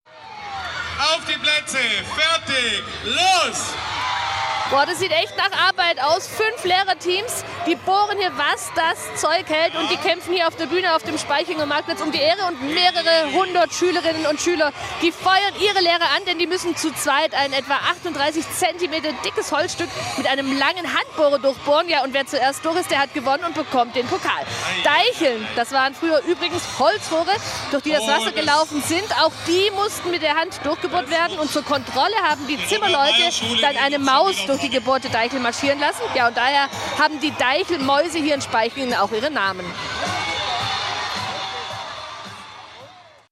Die Schülerinnen und Schüler feuern dabei an.
Beim Deichelbohren in Spaichingen im Zollernalbkreis schauen die Schülerinnen und Schüler auch bei schlechtem Wetter gebannt zu, während ihre Lehrkräfte um die Wette bohren.